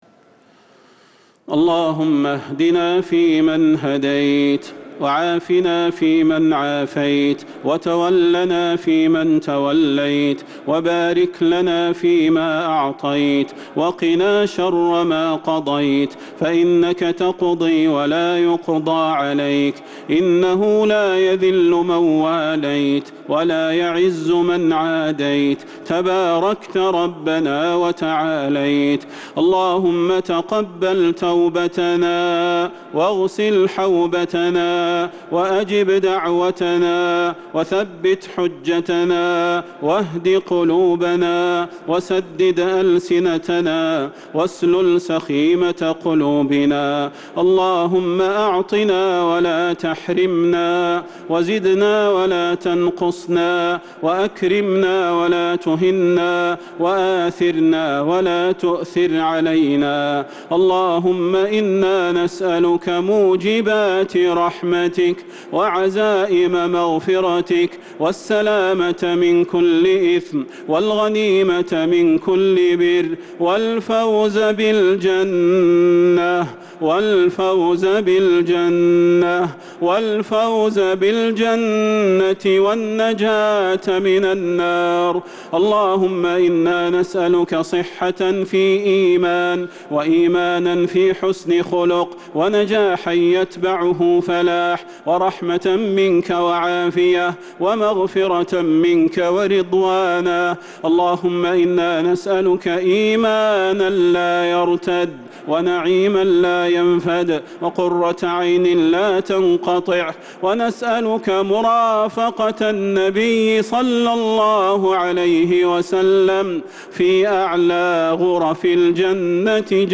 دعاء القنوت ليلة 2 رمضان 1444هـ | Dua 2 st night Ramadan 1444H > تراويح الحرم النبوي عام 1444 🕌 > التراويح - تلاوات الحرمين